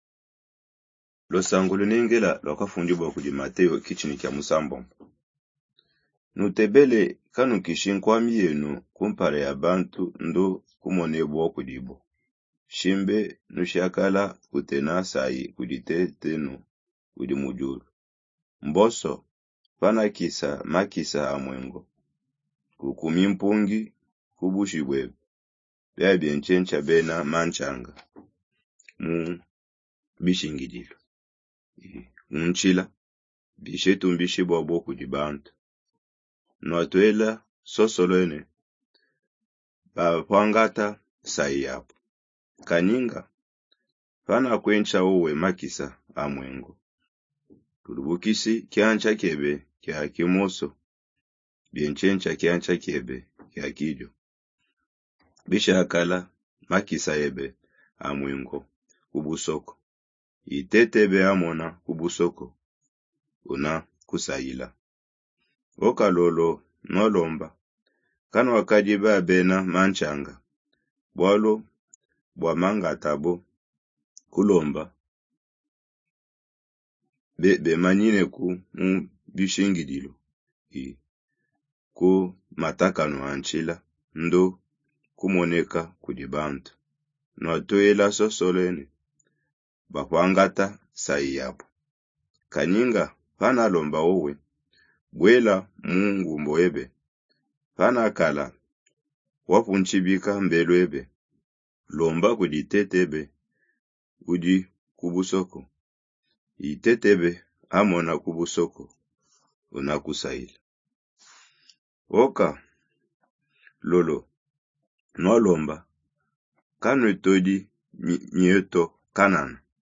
Lecture orale de la Bible en Luna Inkongo